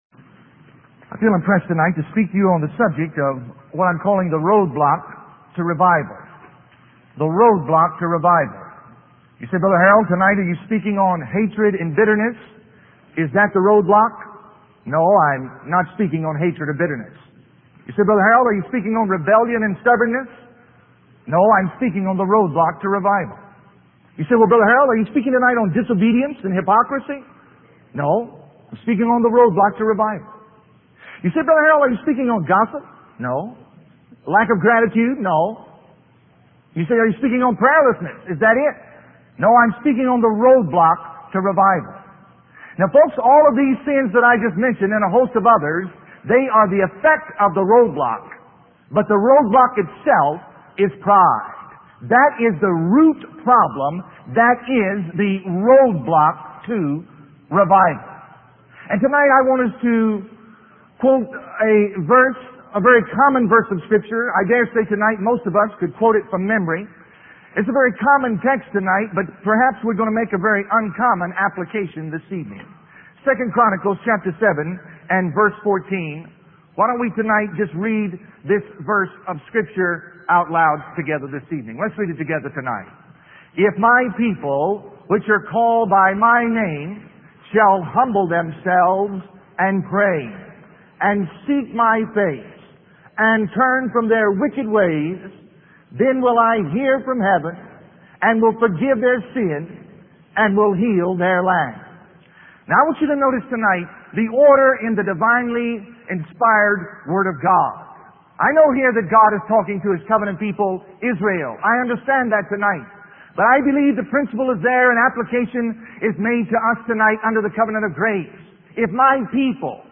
In this sermon, the preacher shares two stories to illustrate the root problem and roadblock to revival. The first story involves a man swinging on a swing near a lion cage, seeking the crowd's approval and worshiping himself.